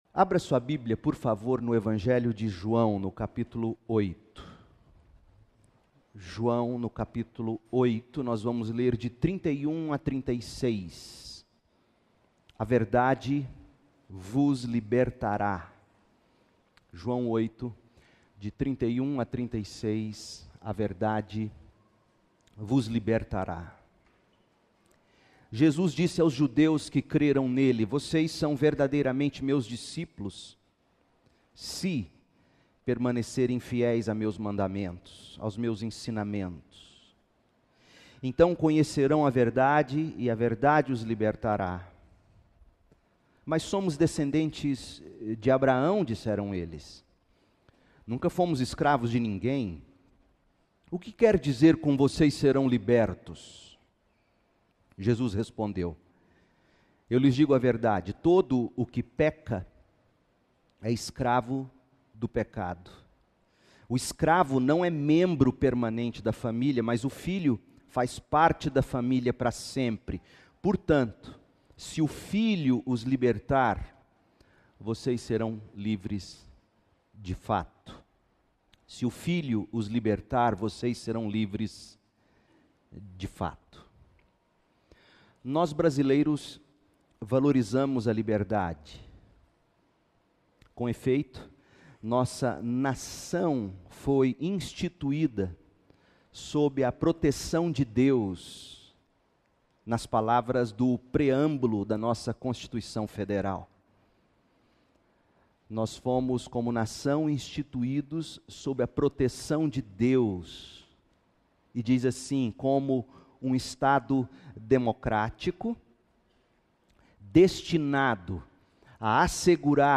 Série: Evangelho de João